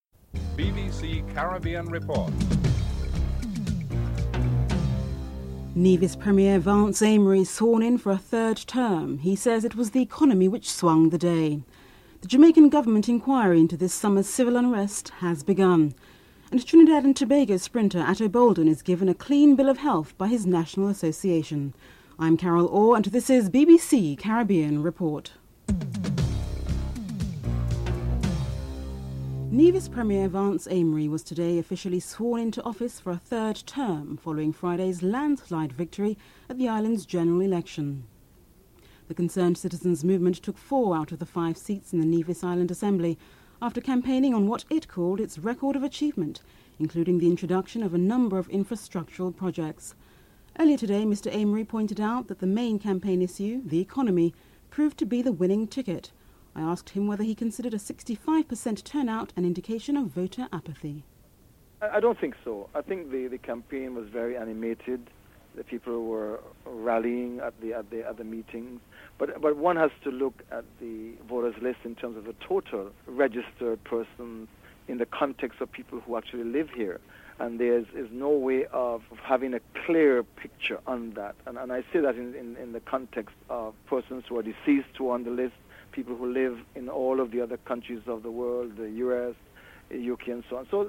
1. Headlines (00:00-00:29)
2. Nevis' Premier Vance Amory is sworn in for a third term and says it was the economy which swung the day. Premier Vance Amory and Leader of Nevis' Reformation Party Joseph Parry are interviewed (00:30-06:40)